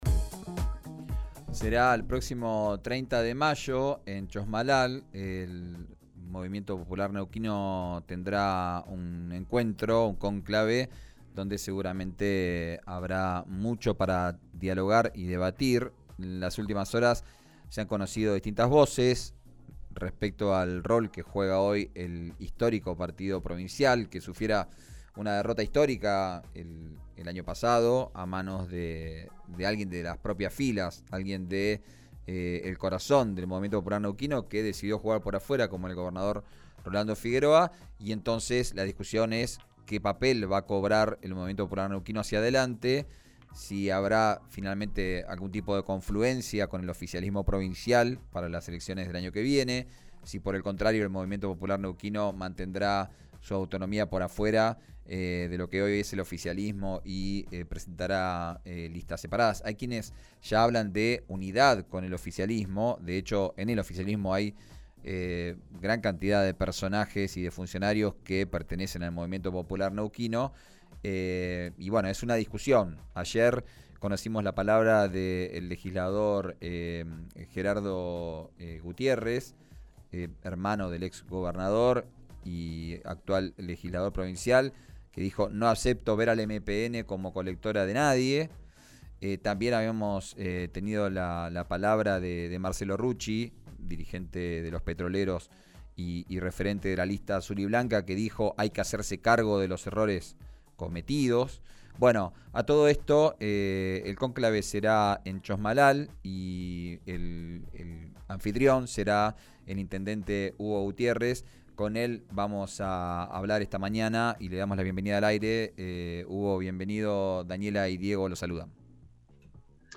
Escuchá a Hugo Gutiérrez, exintendente de Chos Malal, en RÍO NEGRO RADIO: